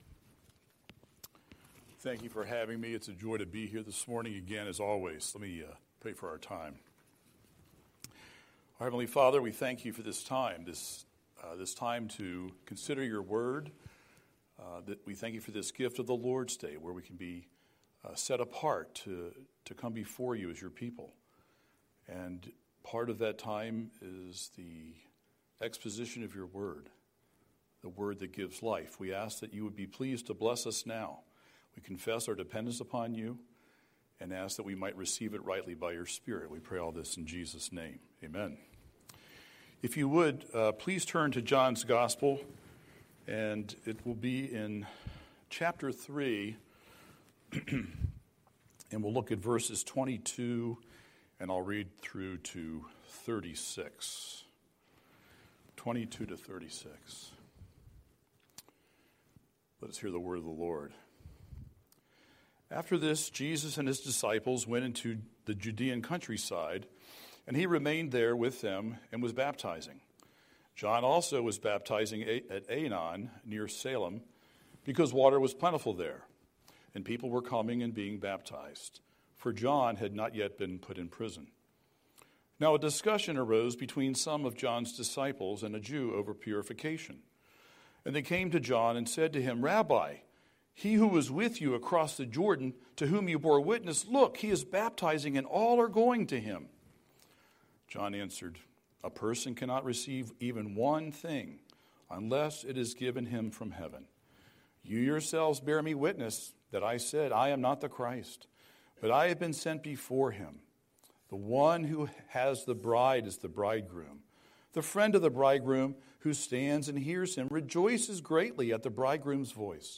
Scripture: John 3:22-36 Series: Sunday Sermon